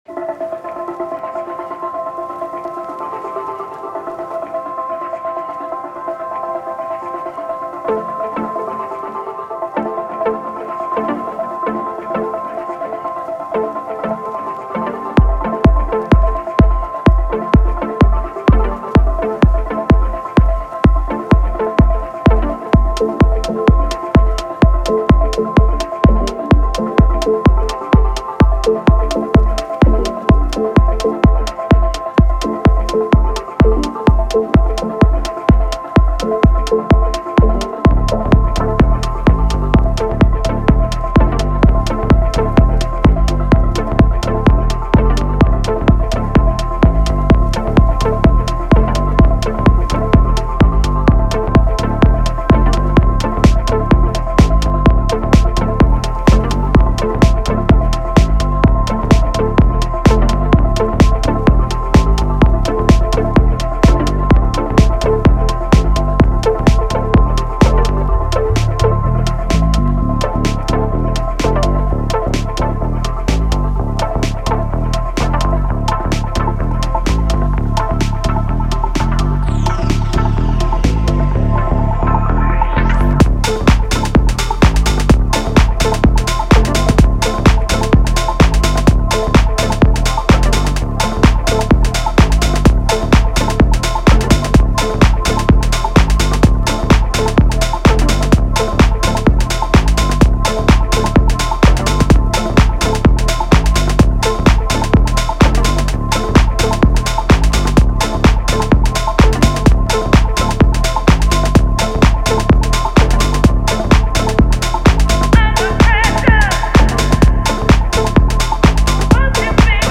The MPC3 has a lot more control and the potential for details for sure - but both are great for house.
Sampled some records and cut up some vocals - :crazy_face: